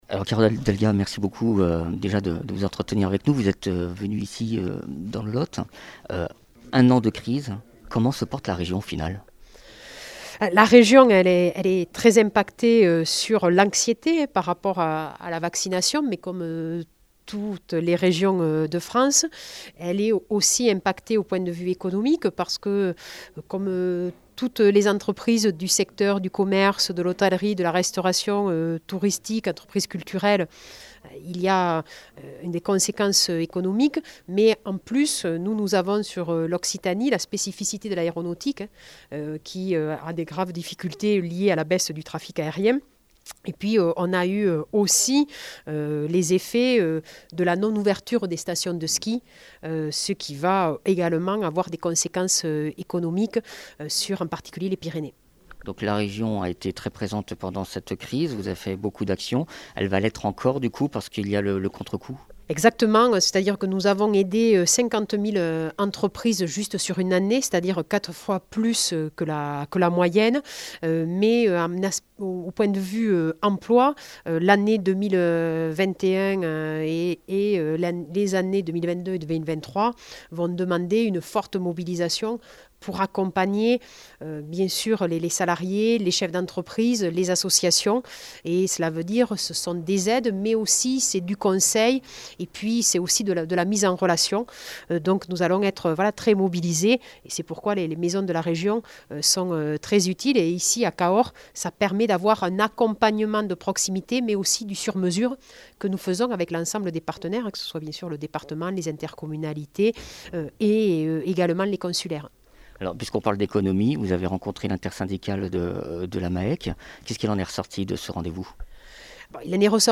Interviews
Invité(s) : Carole Delga, présidente de la région Occitanie